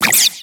Cri de Statitik dans Pokémon X et Y.